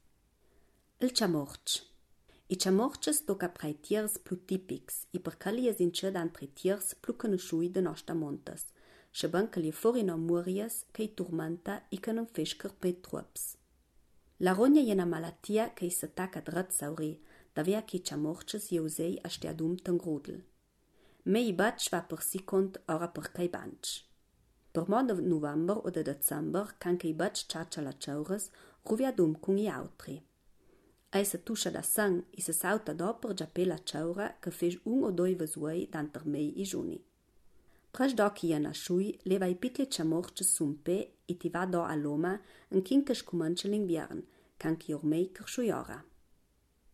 Ladino gardenese